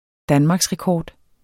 Udtale [ ˈdanmɑgsʁεˌkɒːd ]